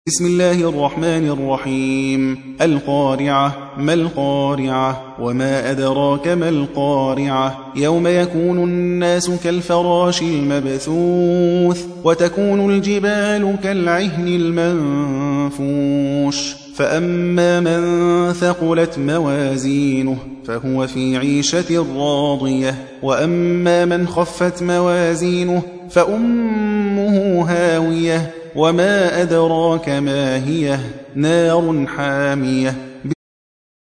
101. سورة القارعة / القارئ